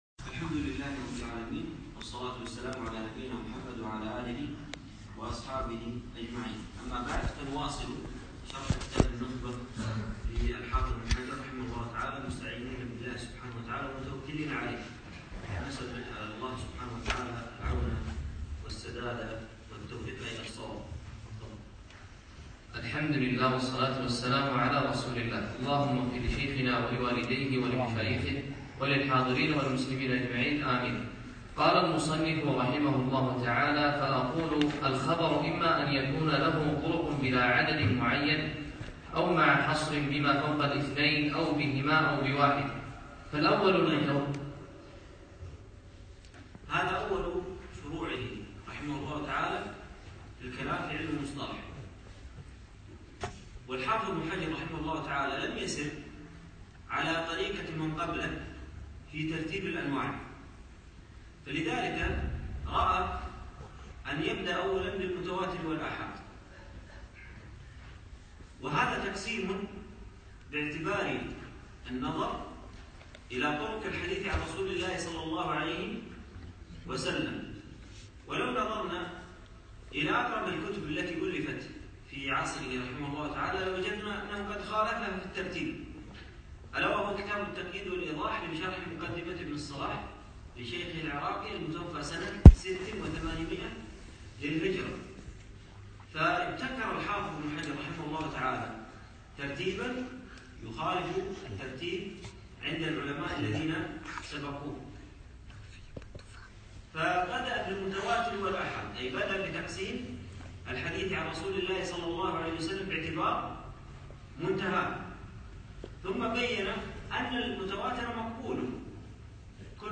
يوم الخميس 5 جمادى أول 1438 الموافق 2 2 2017 في مسجد زين العابدين سعد ال عبدالله